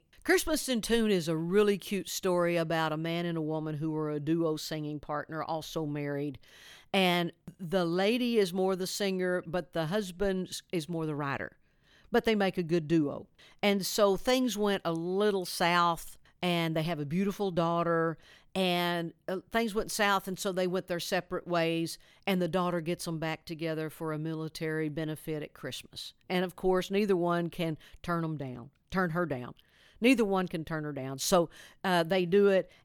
Audio / Reba McEntire talks about her upcoming Lifetime holiday movie, Reba McEntire’s Christmas In Tune.